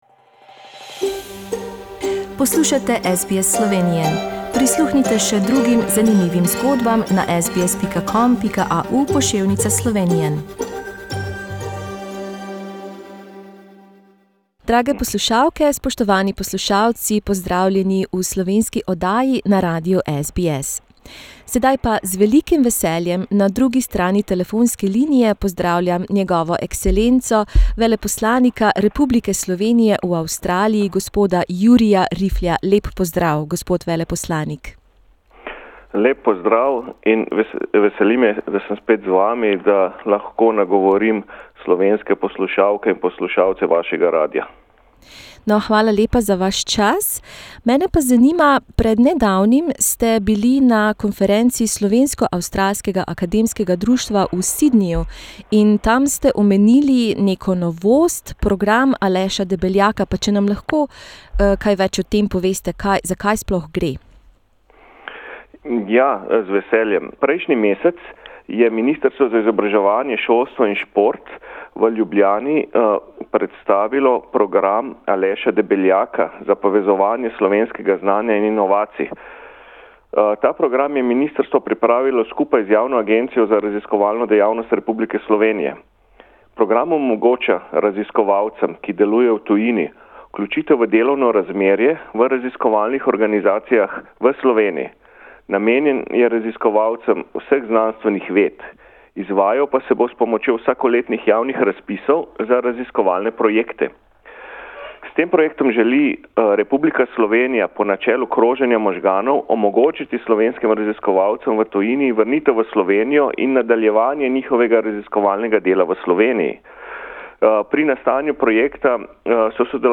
Veleposlanik Republike Slovenije v Avstraliji Jurij Rifelj je predstavil povsem svež razpis za slovenske raziskovalce, ki živijo v tujini.